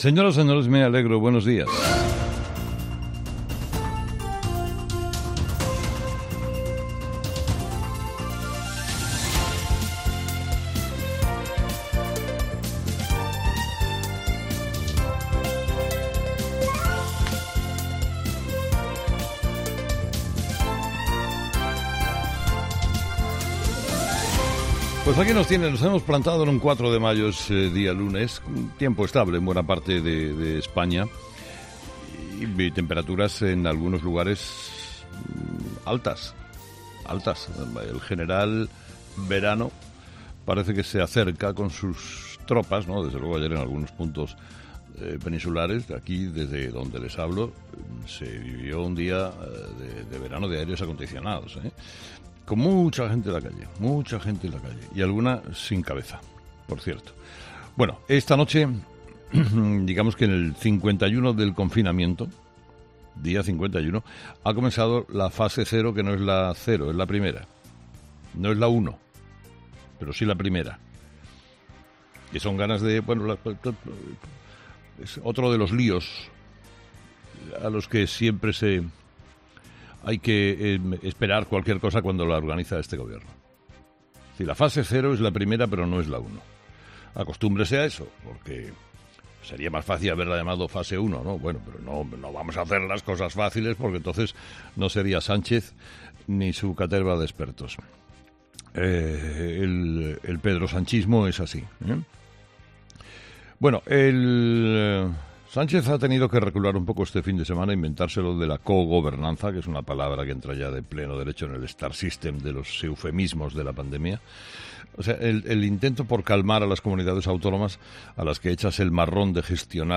ESCUCHA EL EDITORIAL DE CARLOS HERERRA